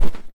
sfx_land.wav